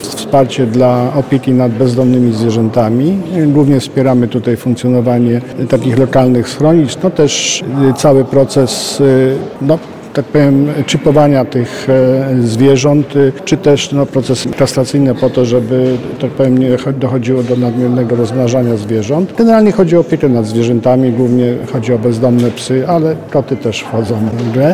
– przekazał marszałek Adam Struzik.